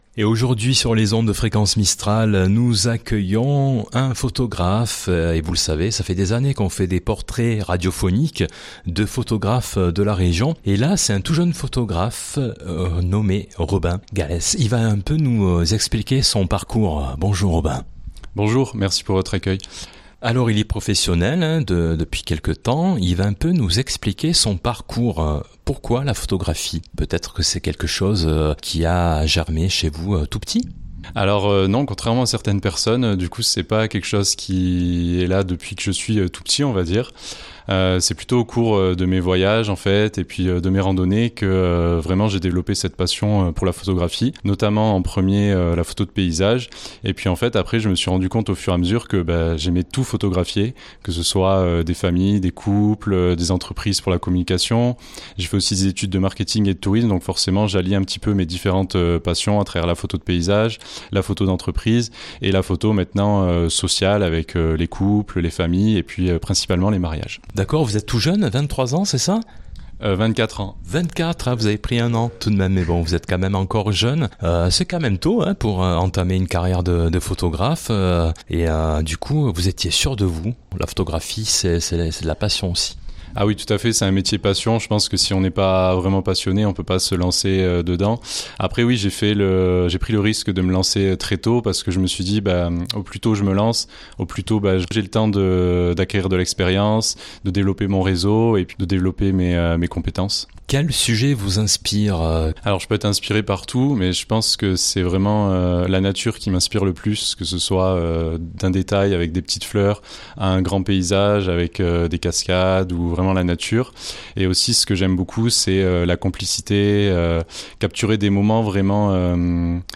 Portrait radiophonique d'un photographe